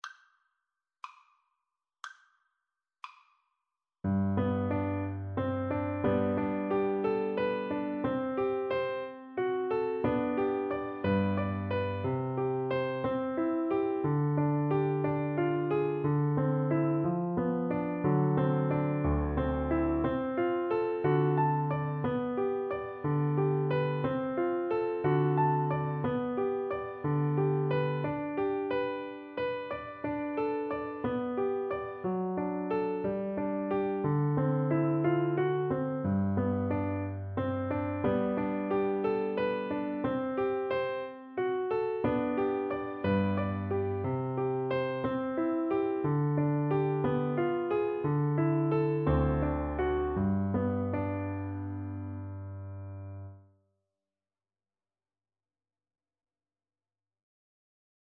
Play (or use space bar on your keyboard) Pause Music Playalong - Piano Accompaniment Playalong Band Accompaniment not yet available reset tempo print settings full screen
~ = 60 Andantino (View more music marked Andantino)
2/4 (View more 2/4 Music)
G major (Sounding Pitch) (View more G major Music for Viola )
Classical (View more Classical Viola Music)